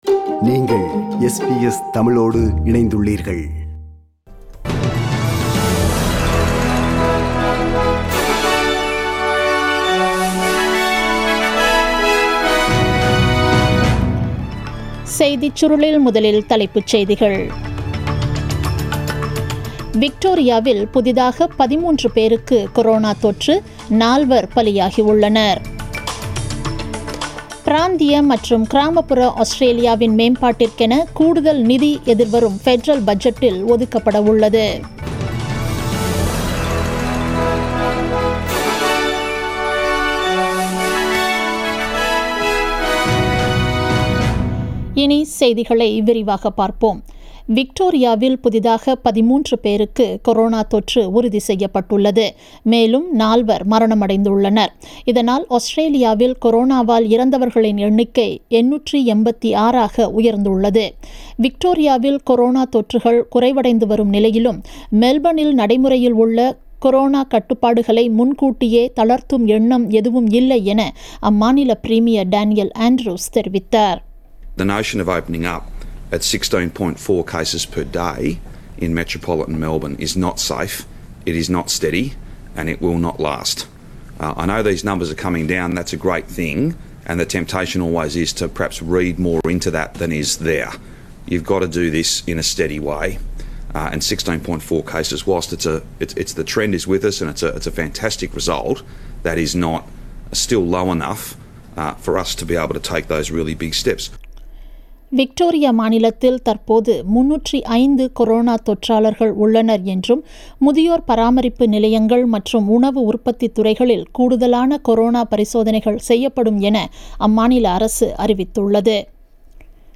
The news bulletin was aired on 30 September 2020 (Wed) at 8pm.